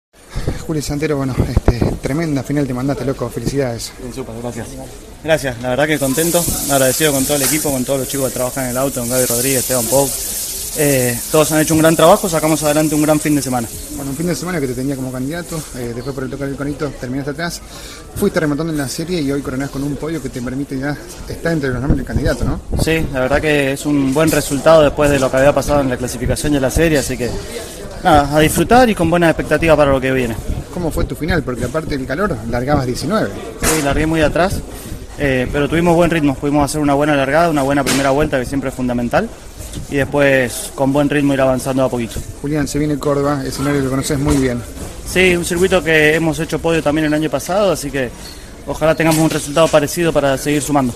Todas las entrevistas, a continuación y en el orden antes mencionado: